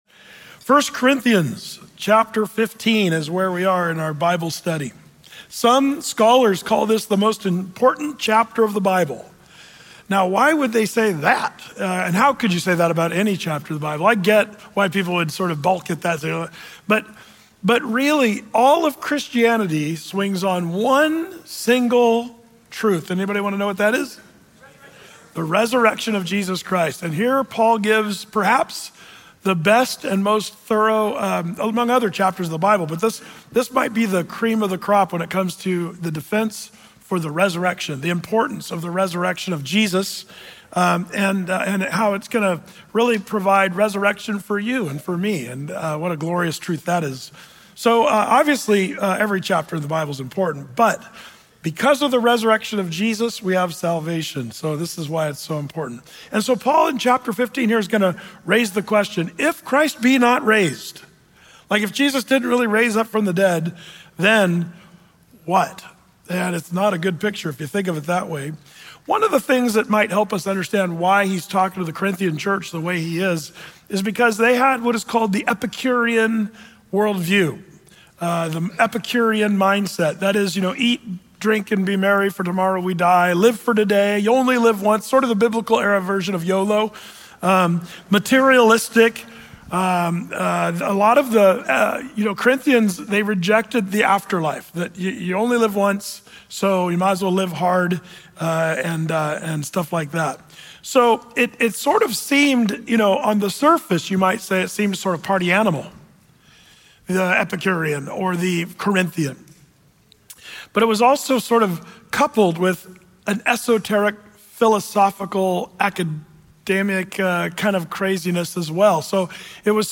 Through-the-Bible teaching